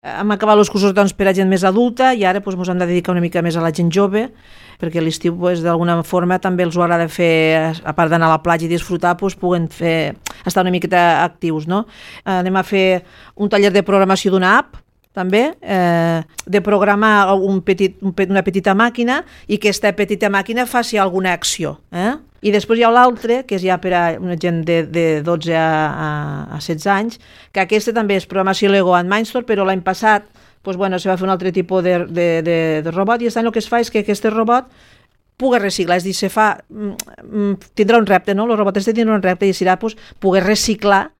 Maria Marsal és la regidora de Formació i Treball: